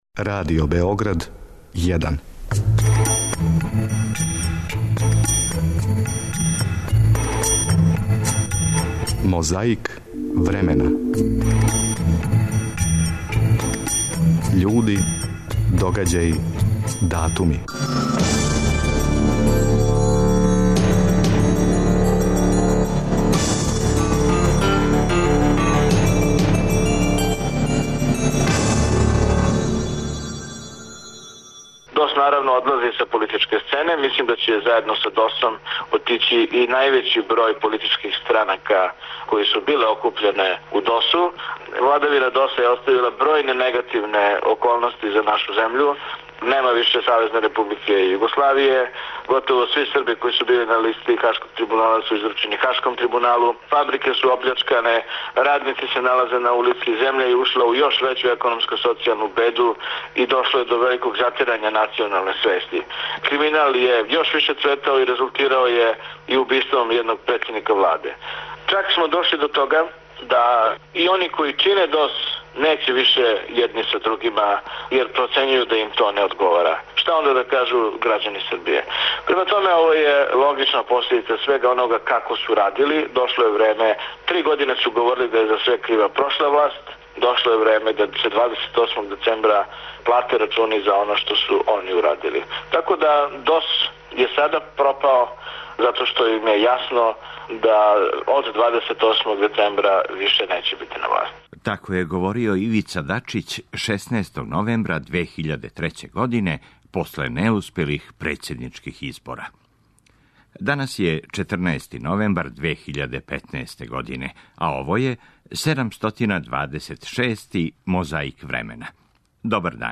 Тако је говорио Ивица Дачић 16. новембра 2003. године после неуспелих председничких избора.